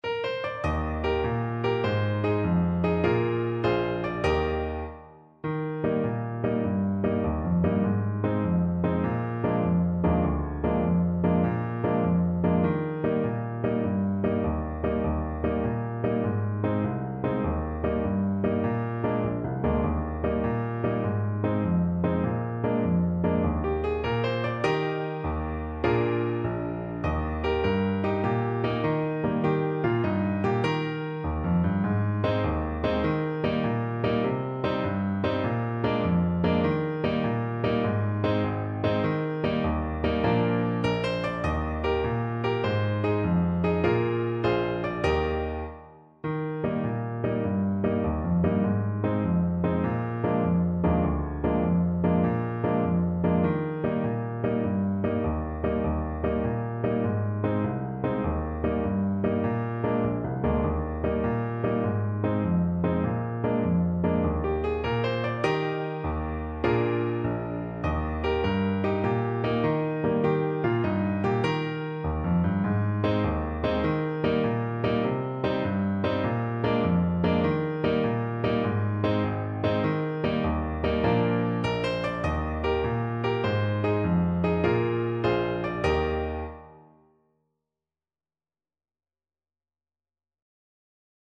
Trumpet
6/8 (View more 6/8 Music)
Eb major (Sounding Pitch) F major (Trumpet in Bb) (View more Eb major Music for Trumpet )
Allegro .=c.100 (View more music marked Allegro)
Traditional (View more Traditional Trumpet Music)
home_boys_home_TPT_kar1.mp3